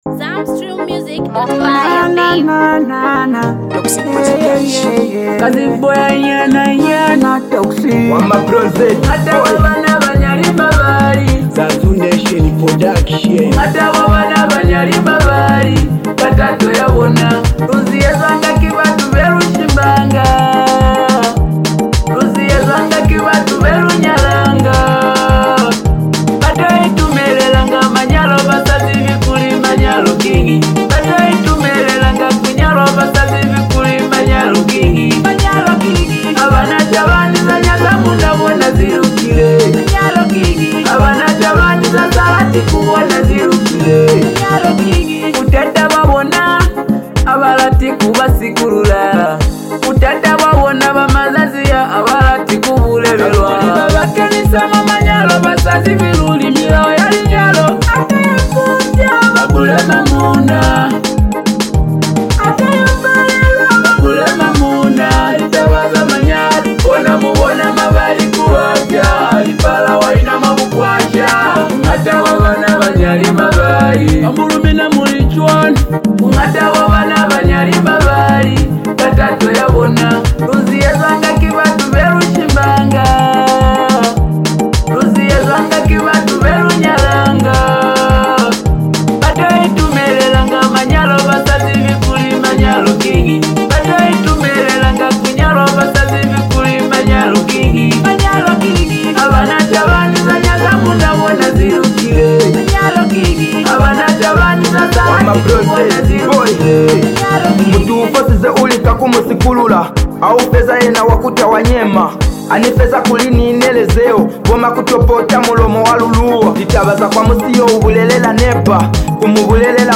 vibrant sound